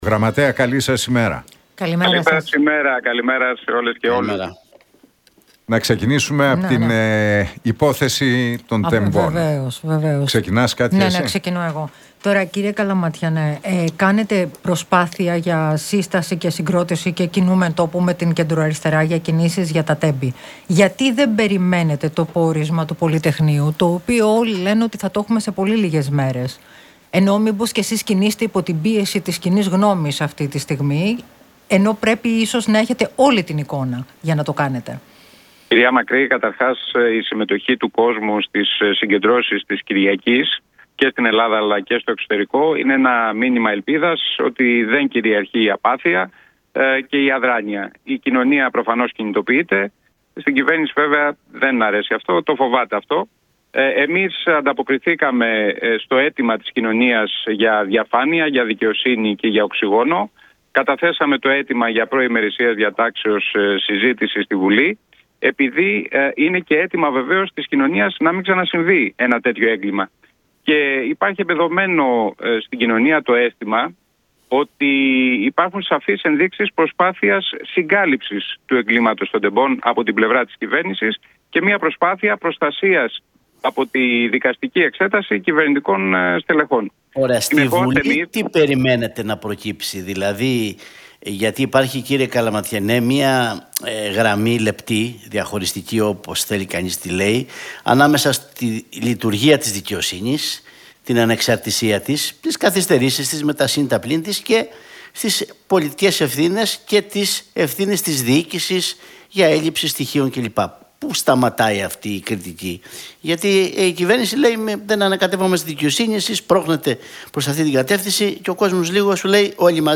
Καλαματιανός στον Realfm 97,8: Είναι μία κυβέρνηση που ταλαιπωρεί τον κόσμο - Εμείς πρωτίστως απευθυνόμαστε στο ΠΑΣΟΚ και την Νέα Αριστερά
μίλησε ο γραμματέας της Κοινοβουλευτικής Ομάδας του ΣΥΡΙΖΑ, Διονύσης Καλαματιανός
από την συχνότητα του Realfm 97,8.